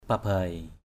/pa-baɪ/ 1. (đg.) rải ra. 2.
pabai.mp3